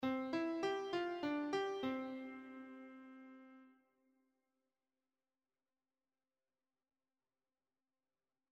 compTimeSign.mp3